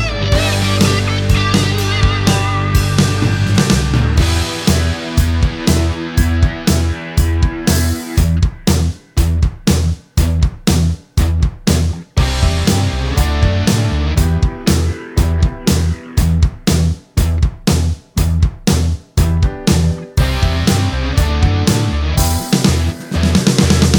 no Backing Vocals Rock 3:33 Buy £1.50